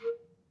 Clarinet
DCClar_stac_A#3_v1_rr1_sum.wav